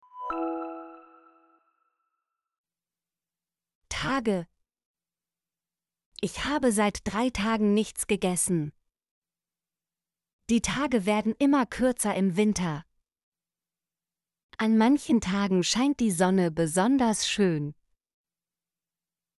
tage - Example Sentences & Pronunciation, German Frequency List